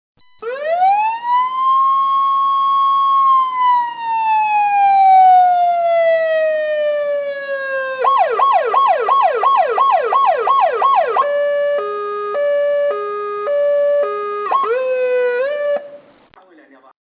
2 – نغمة دورية الشرطه